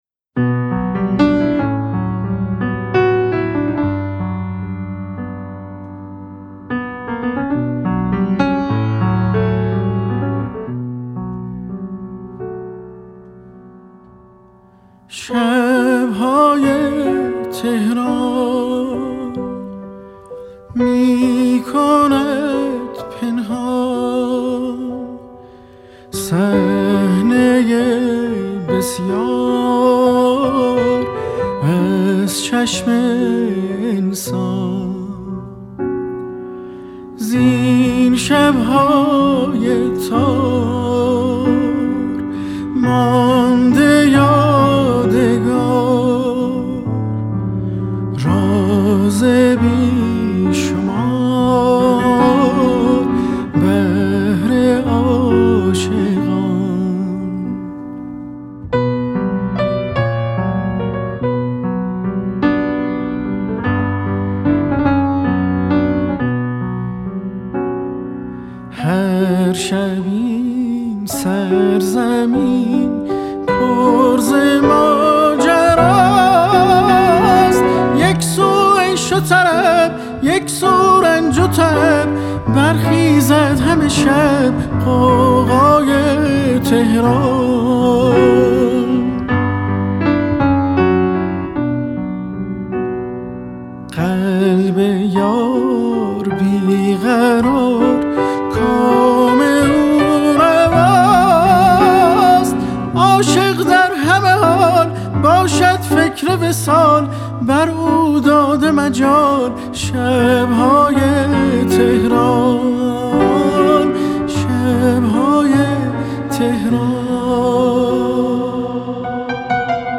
تنظیم و پیانو